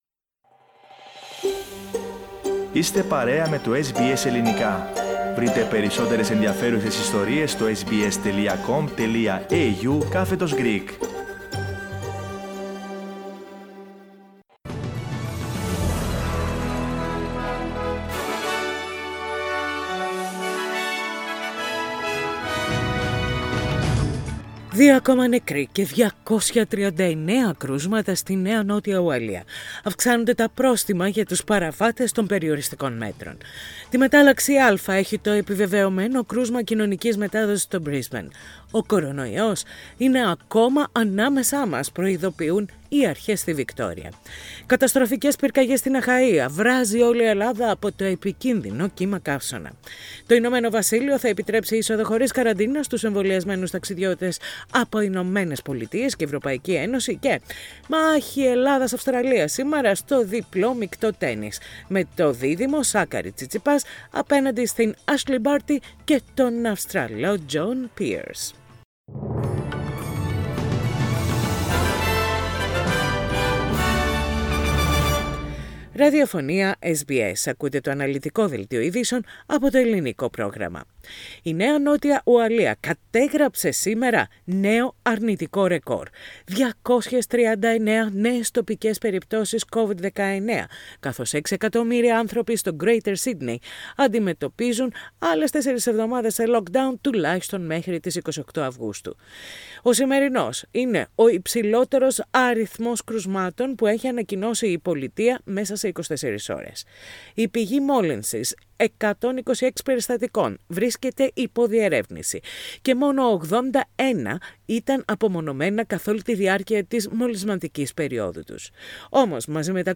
News in Greek - Thursday 29.7.21
Main news of the day from SBS Radio Greek.